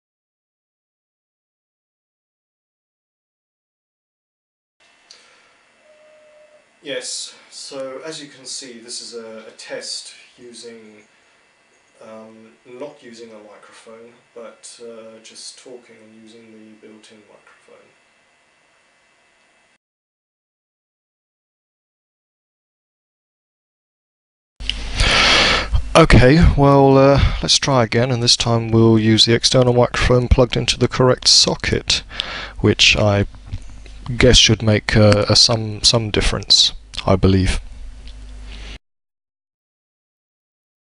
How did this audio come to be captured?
Quick test to compare the cameras built in microphone to a cheap external microphone.